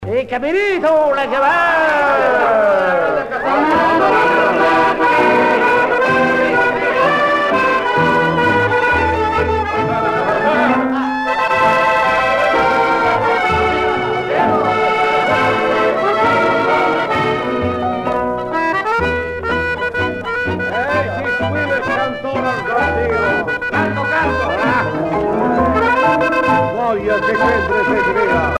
danse : tango musette